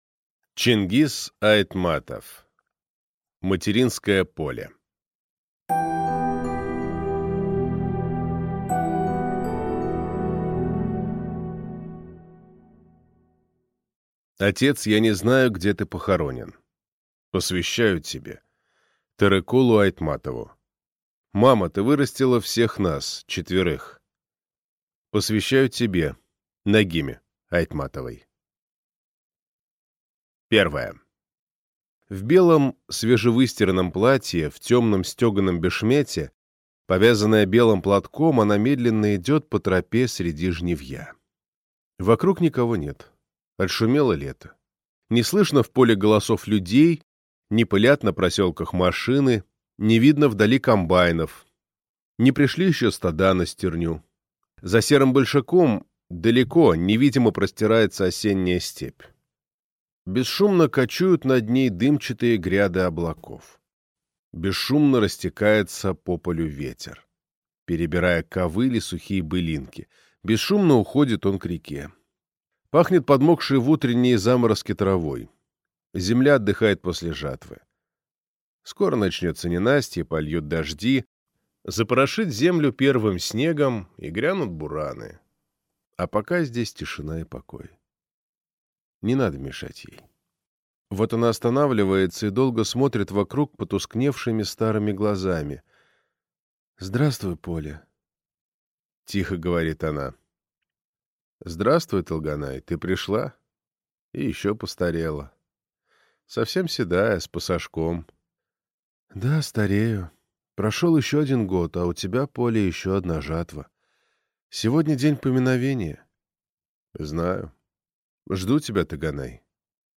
Аудиокнига Материнское поле | Библиотека аудиокниг